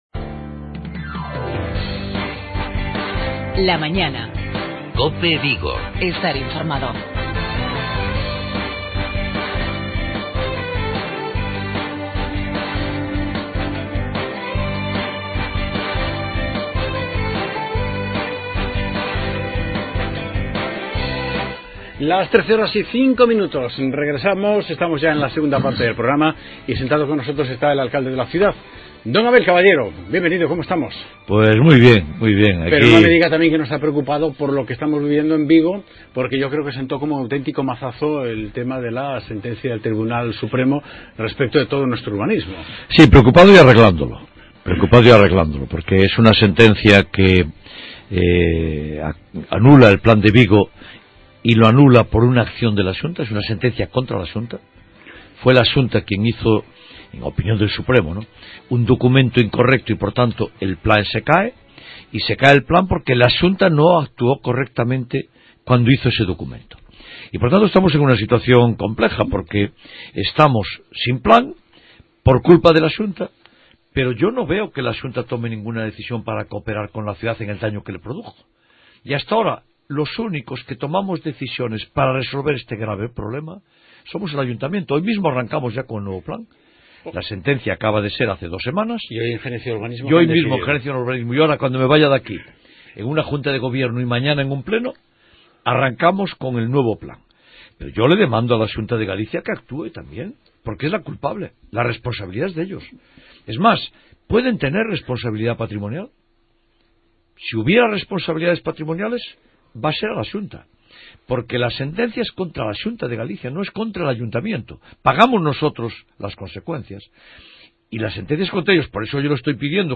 En la segunda parte del programa recibiremos a Abel Caballero, alcalde de Vigo, sobre temas de actualidad municipal.
Cerraremos el programa con la mejor música de los años 60.